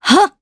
Isolet-Vox_Attack4_jp.wav